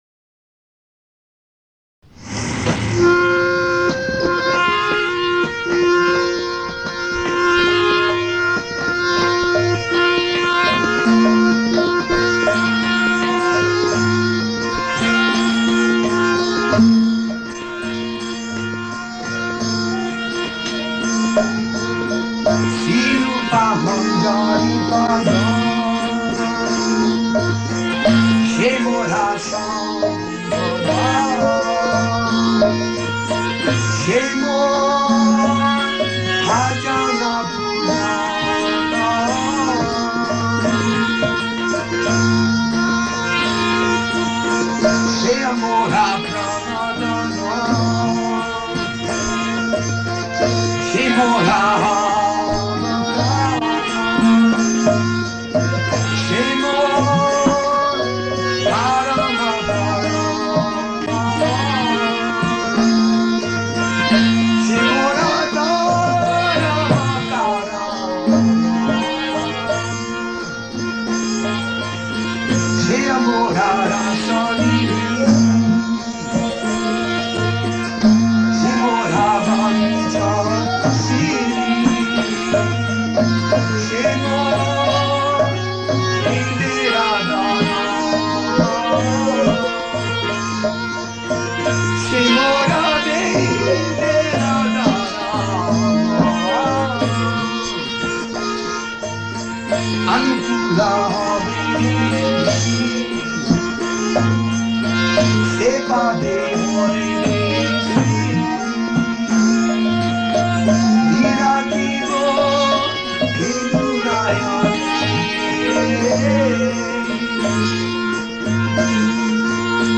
sing this bhajan and recite translation